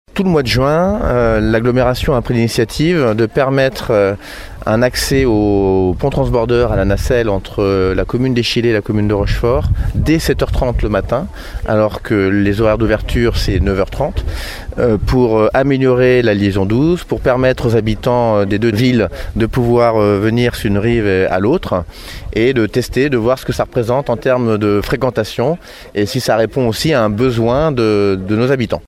Son président et maire de Rochefort Hervé Blanché nous en dit plus :